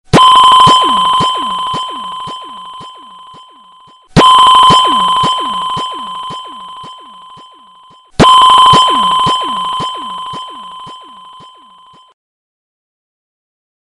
Dzwonek - Radar
Intensywny powtarzający się dźwięk radaru.
radar.mp3